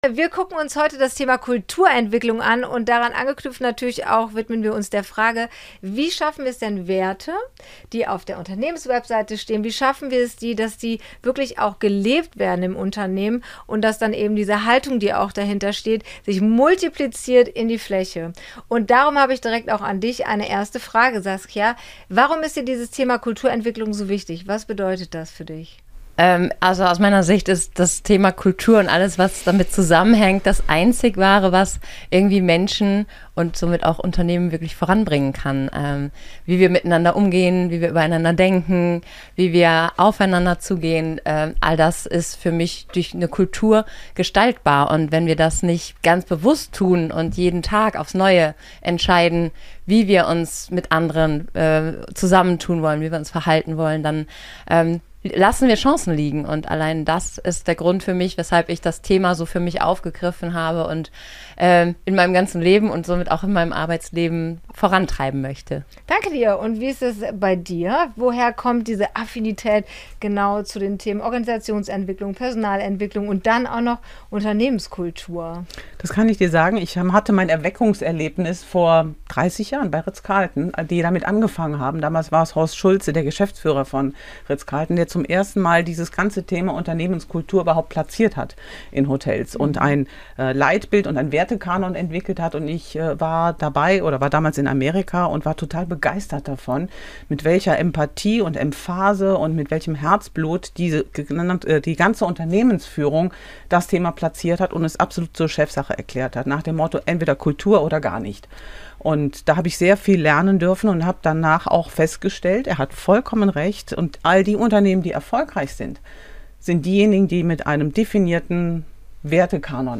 Gedreht wurde im Eventflugzeug auf dem euronova CAMPUS in Hürth.